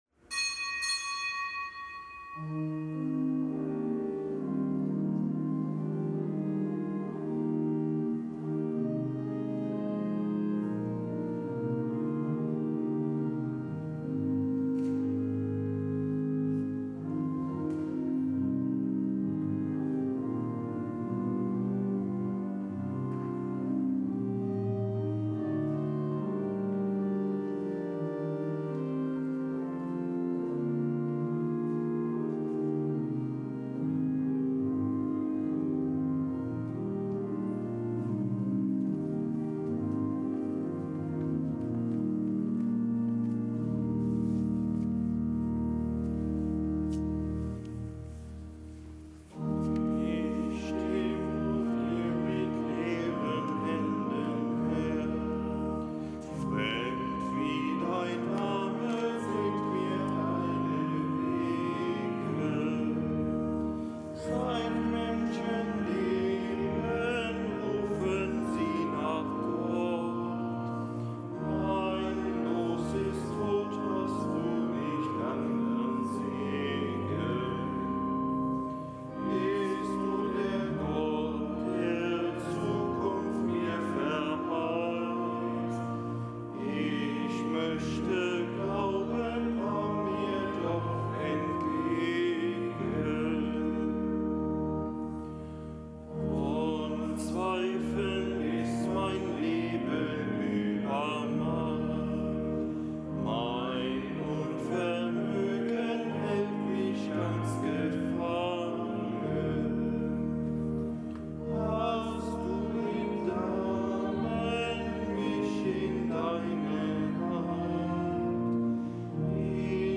Kapitelsmesse aus dem Kölner Dom am Dienstag der dreiunddreißigsten Woche im Jahreskreis.